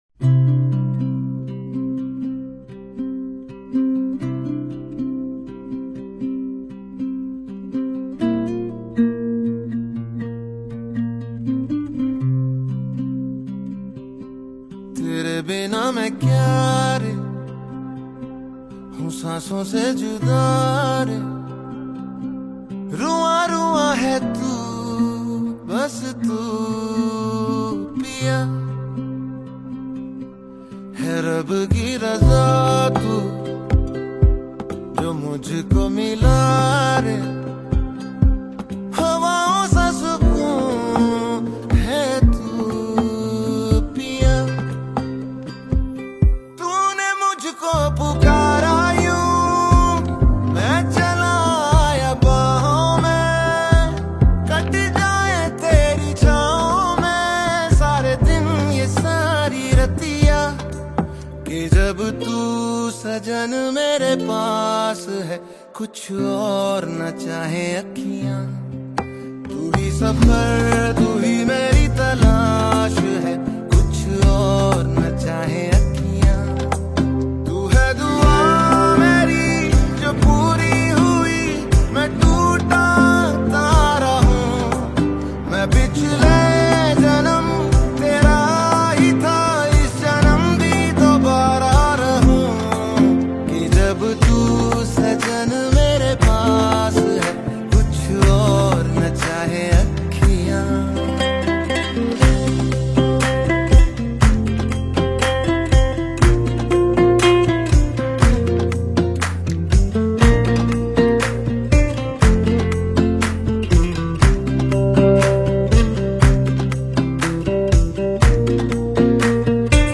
romantic Hindi song
soulful voice touches the heart.
Its soft and romantic tone can also make a cute ringtone.
Bollywood Songs